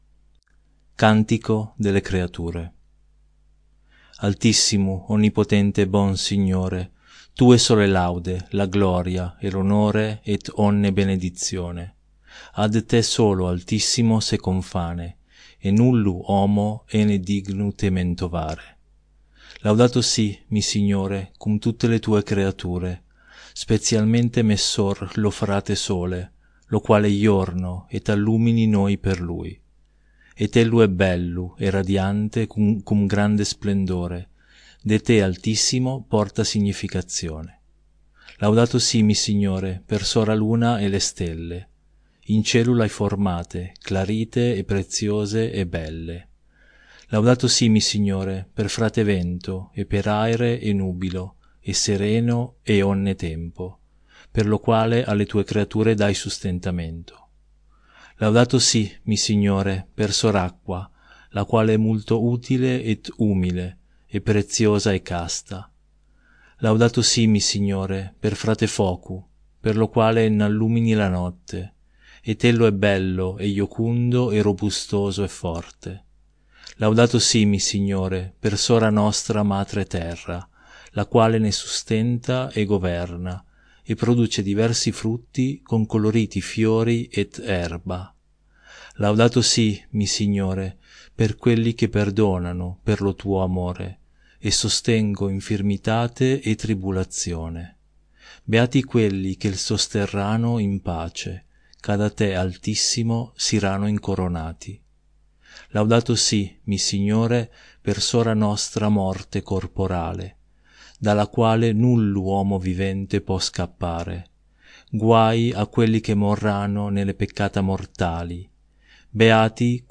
Librivox recording